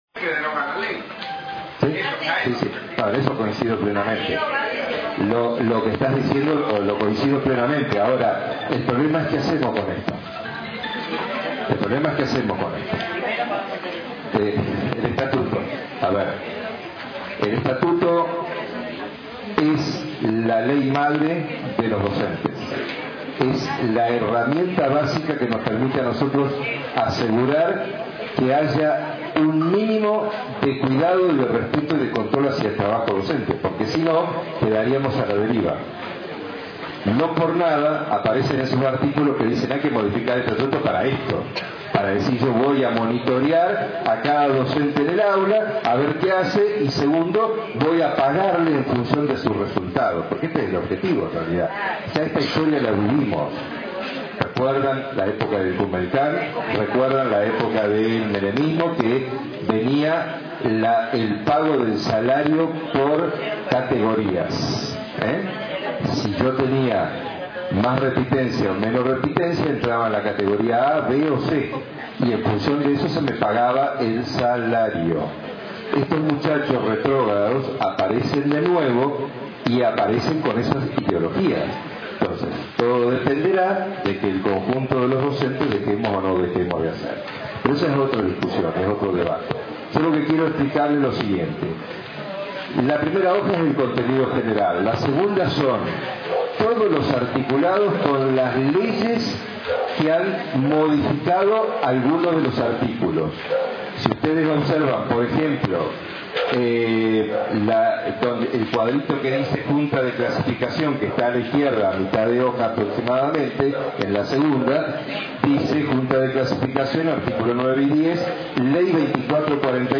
Jornadas de formación para Concurso de Supervisores/as